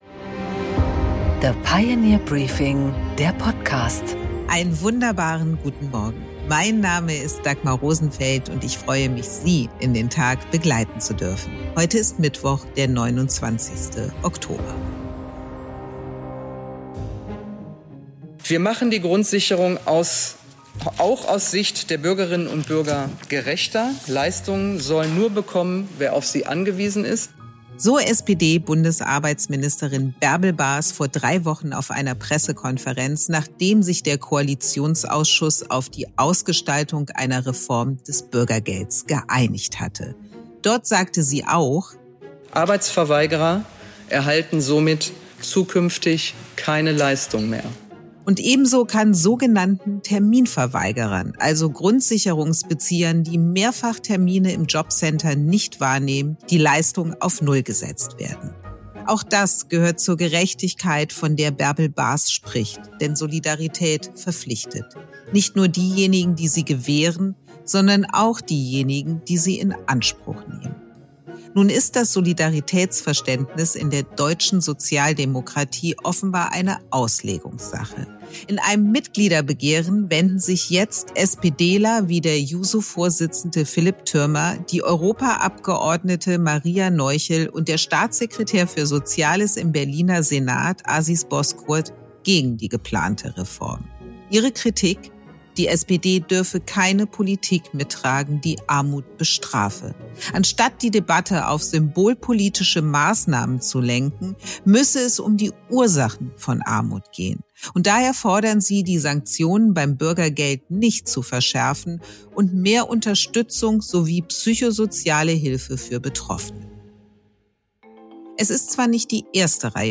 Dagmar Rosenfeld präsentiert das Pioneer Briefing.
Im Gespräch: Prof. Moritz Schularick - Ökonom und Präsident des Kiel‑Instituts für Weltwirtschaft- erklärt im Gespräch mit Dagmar Rosenfeld, warum die Bundesrepublik und Europa im Sandwich zwischen China und den USA gefangen ist.